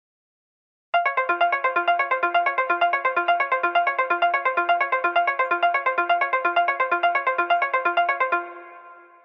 合成器循环 " 失眠者Snyth循环 - 声音 - 淘声网 - 免费音效素材资源|视频游戏配乐下载
简单的琶音采摘合成器循环，来自我的旧轨道。